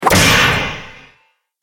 دانلود آهنگ نبرد 12 از افکت صوتی انسان و موجودات زنده
جلوه های صوتی